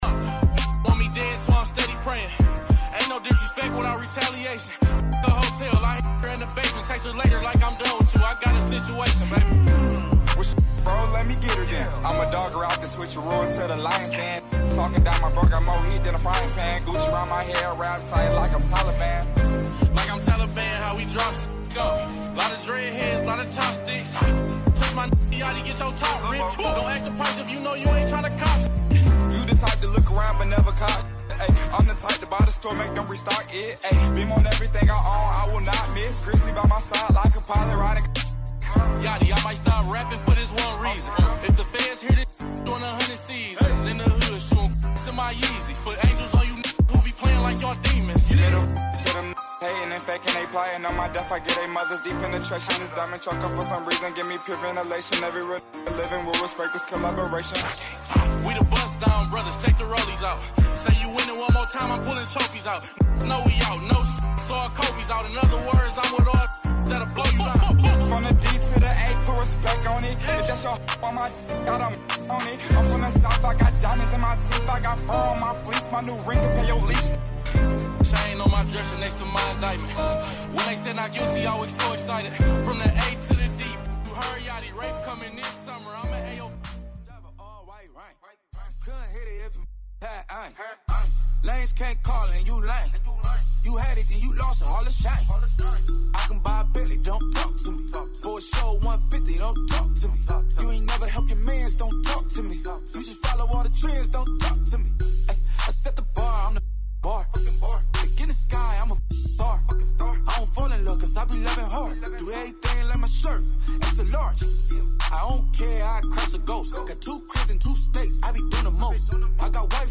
On this show, you’ll hear the recent news, personal experiences and a diverse selection of music.